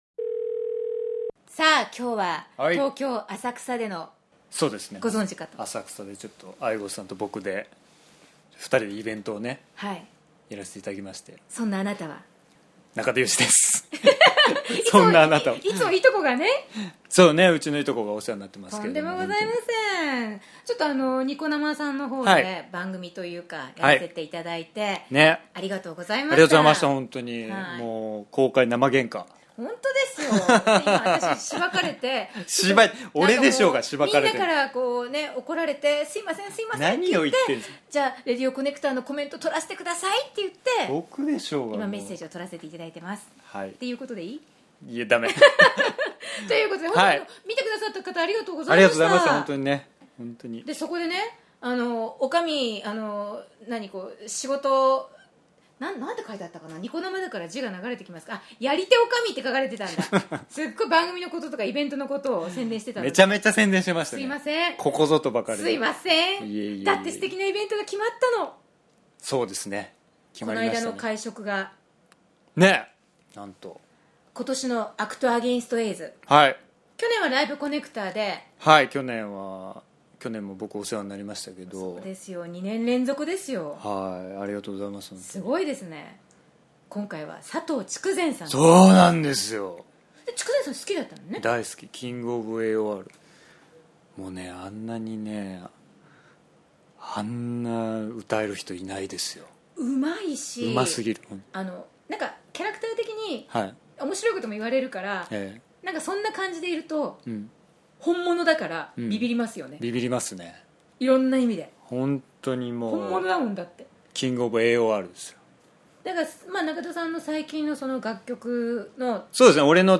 今回は久々に中田裕二氏のインタビュー、
ニコ生さんイベント直後に収録。